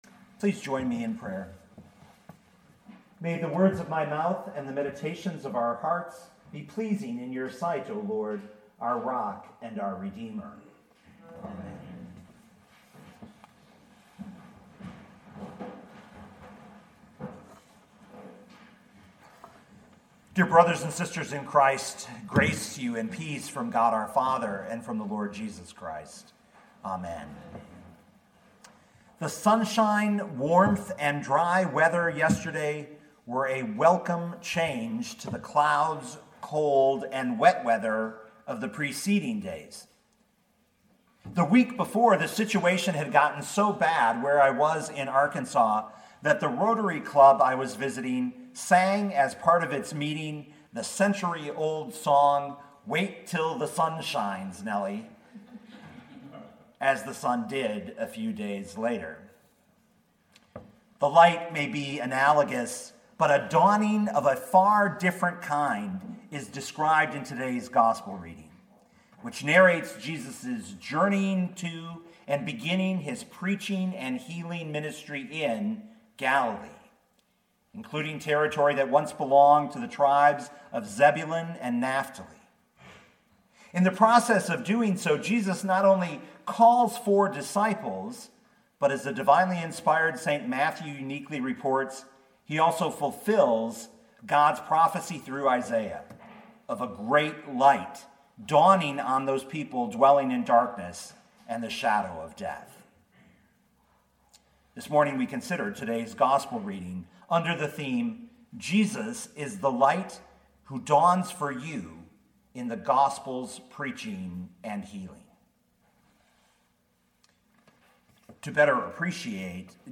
2020 Matthew 4:12-25 Listen to the sermon with the player below, or, download the audio.